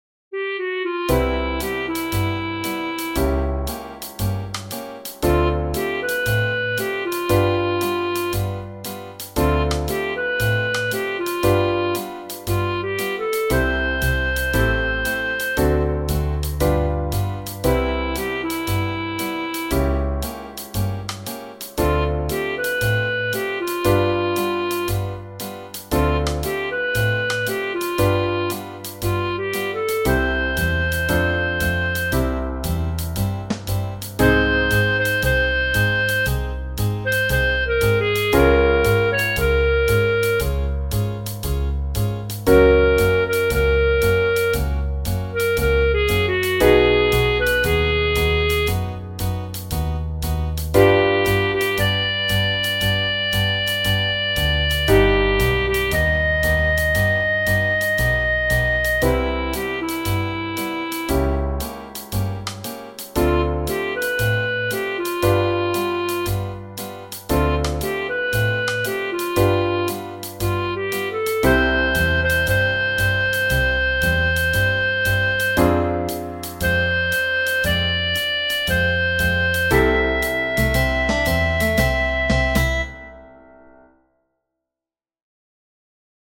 Genere: Jazz